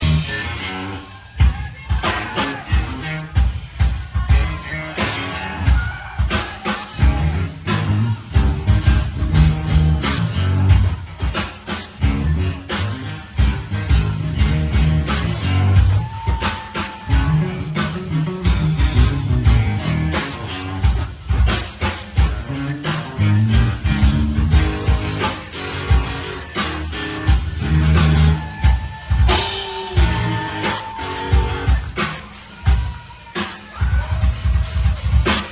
live in Toronto